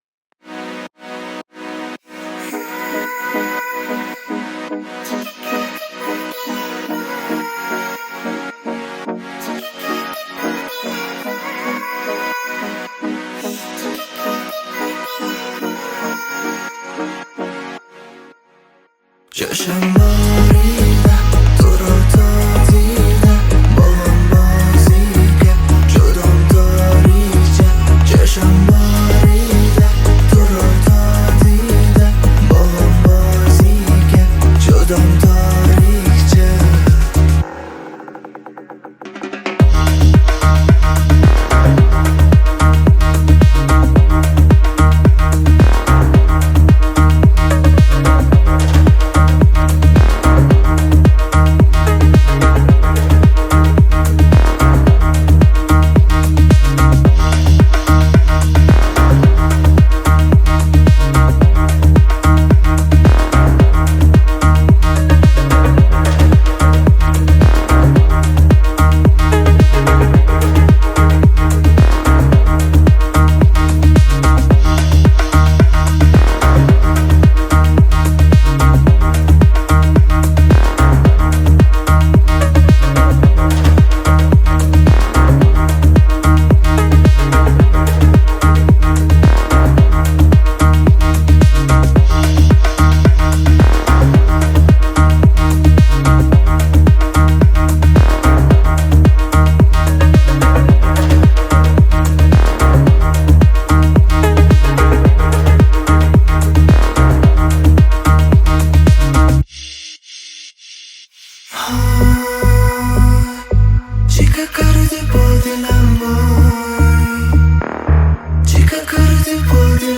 Afrohouse Remix version